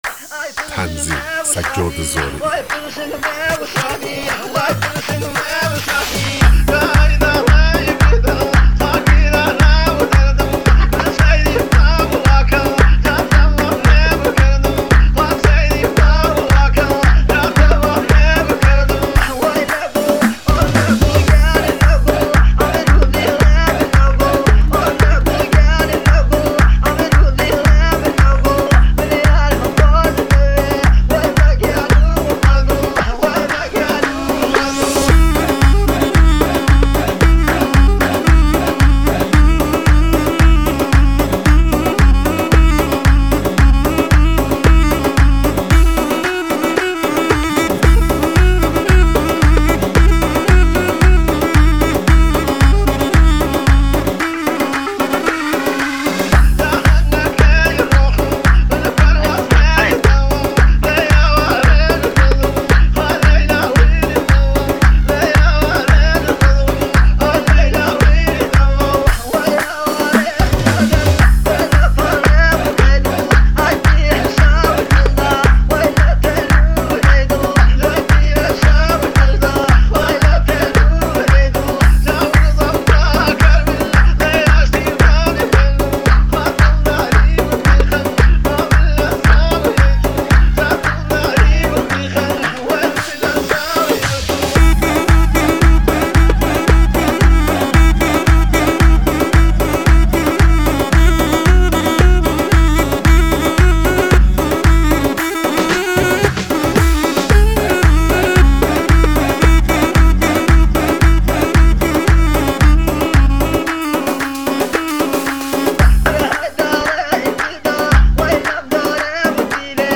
ریمیکس های کردی